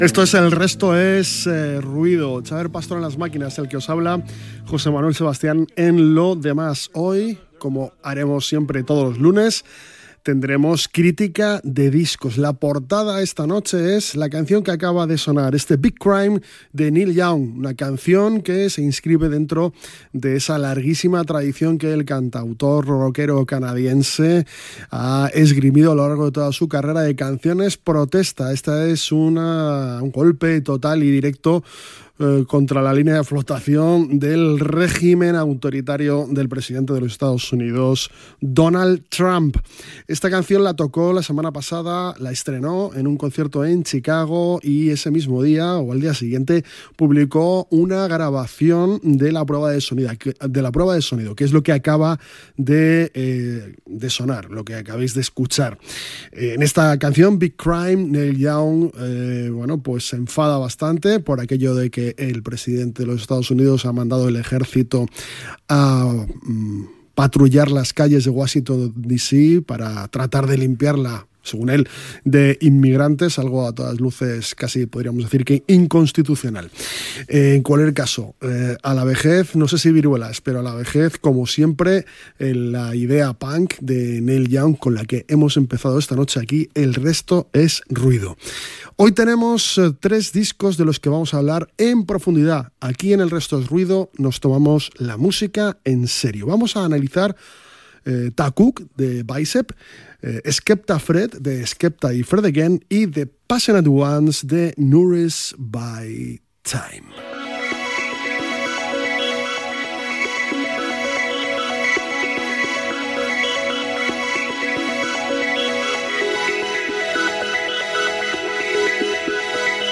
Careta del programa.
Musical